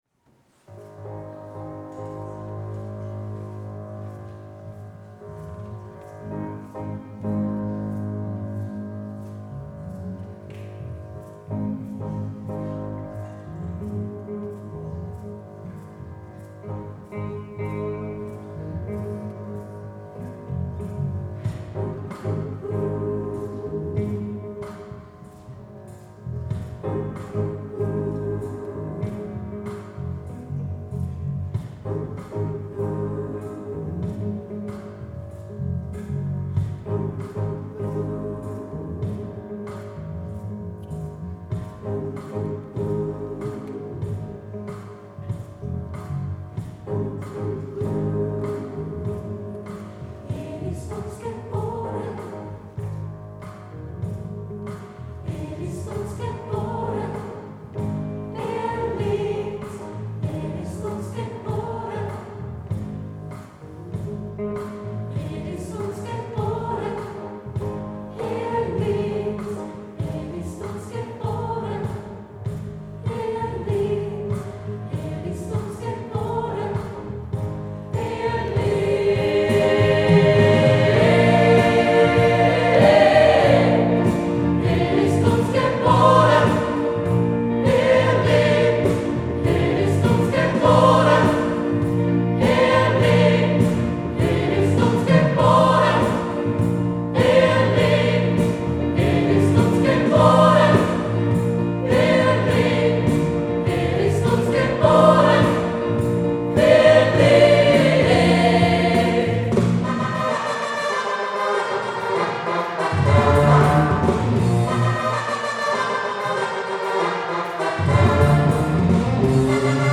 Halleluja  (Live 2017 - Händel Rocks)